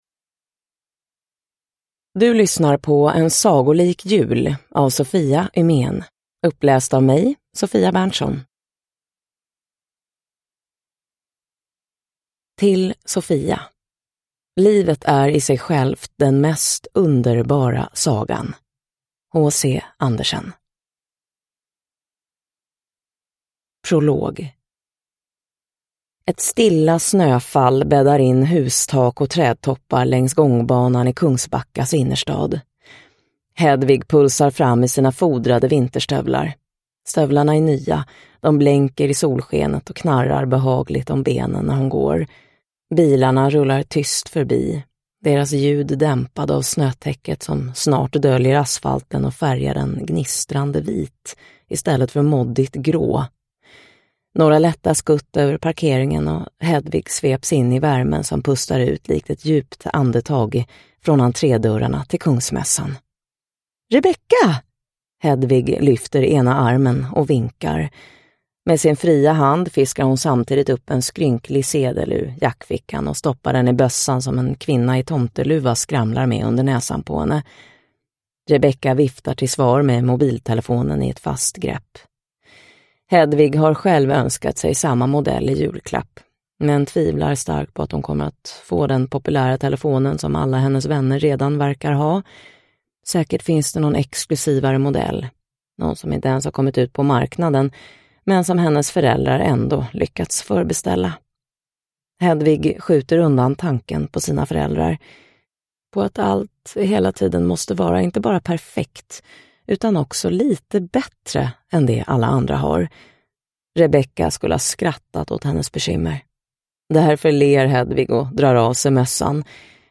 En sagolik jul – Ljudbok – Laddas ner